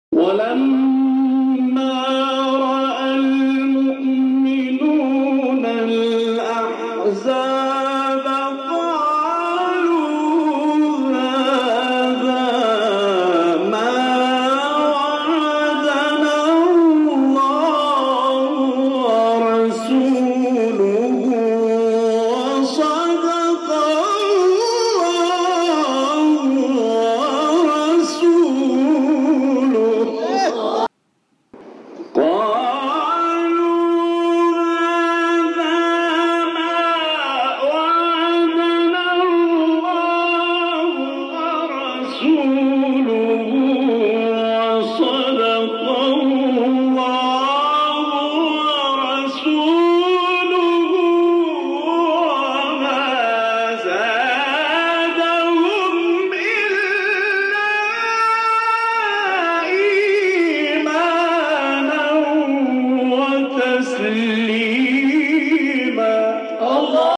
گروه فعالیت‌های قرآنی: مقاطع زیبا و شنیدی از قاریان مصری و ایرانی که در کانال‌ها و گروه‌های قرآنی تلگرام منتشر شده است، ارائه می‌شود.